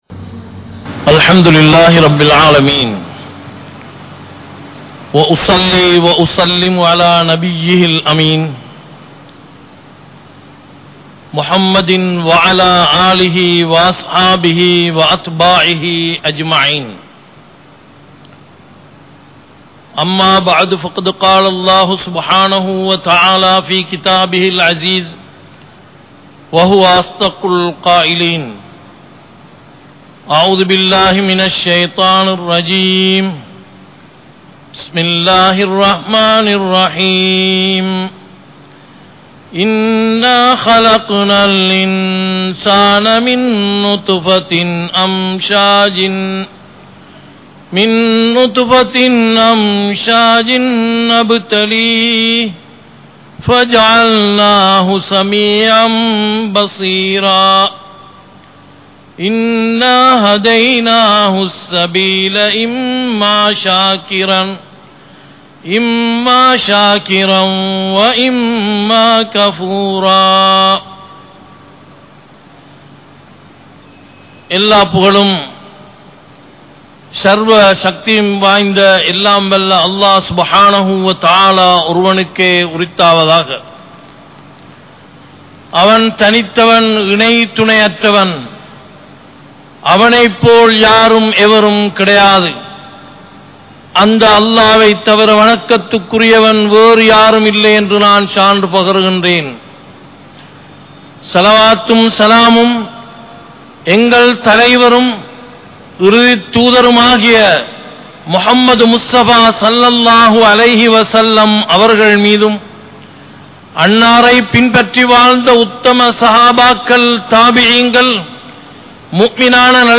Allah`vukku Nantri Ullavaraaha Irungal (அல்லாஹ்வுக்கு நன்றி உள்ளவராக இருங்கள்) | Audio Bayans | All Ceylon Muslim Youth Community | Addalaichenai